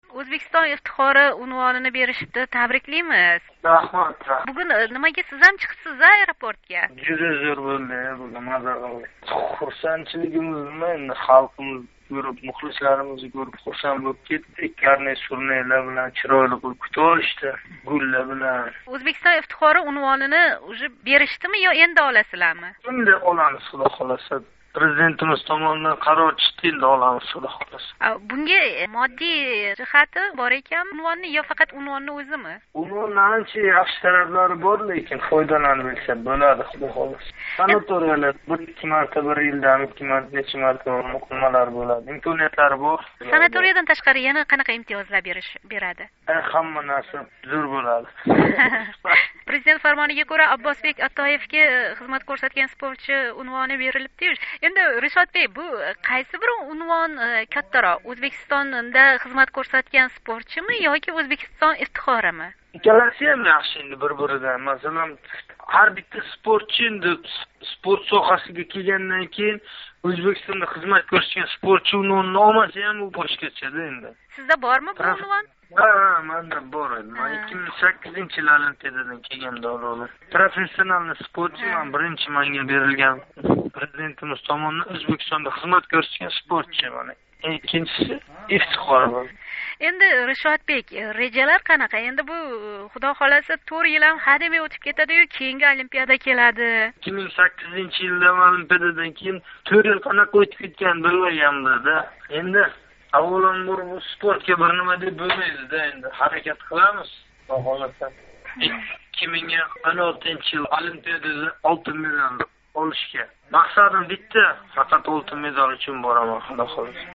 Ришод Собиров билан суҳбат